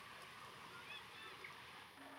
Pullasorsapaikalla äänitetty.
voisiko_olla_joku_lintu_mika_lintu.mp3